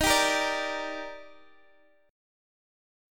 Listen to D#M7 strummed